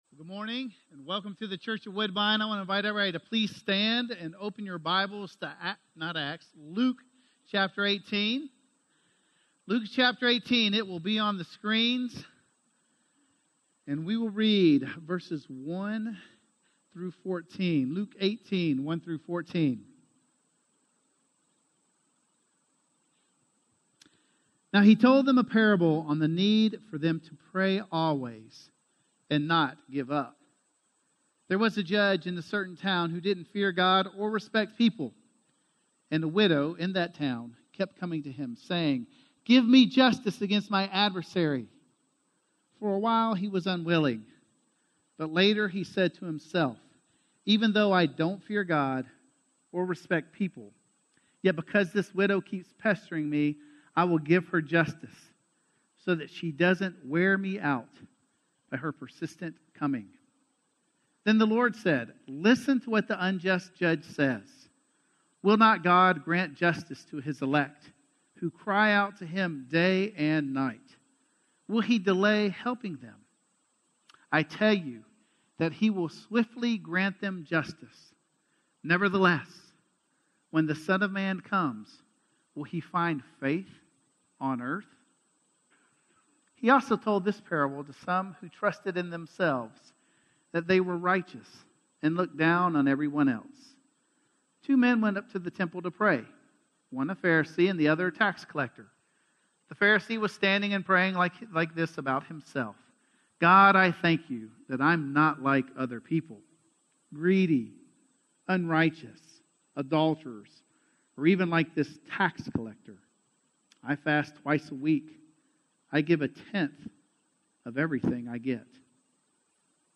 Prayer - Sermon - Woodbine